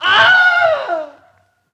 Ohh
Category 🗣 Voices
male meme sweetness vocalization sound effect free sound royalty free Voices